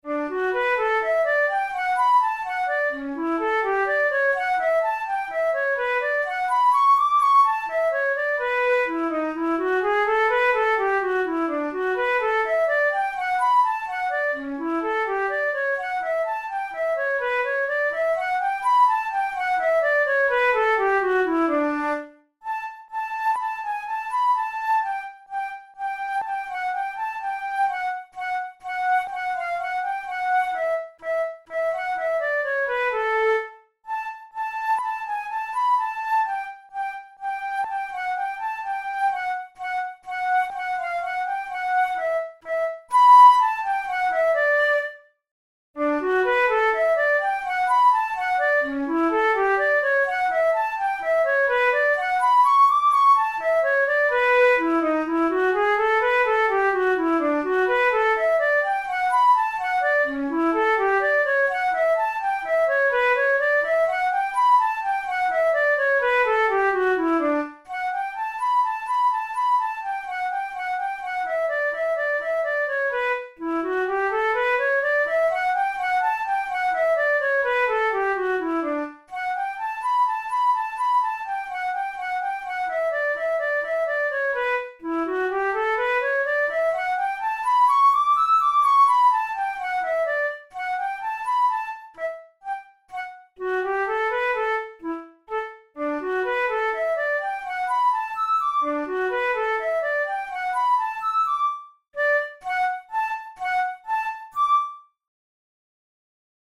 This Allegretto in waltz time starts off with a succession of eighth notes marked “deciso” (determined, resolute), which gives way to a lighter, graceful theme.
Categories: Etudes Romantic Written for Flute Difficulty: easy